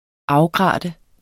Udtale [ -ˌgʁɑˀdə ]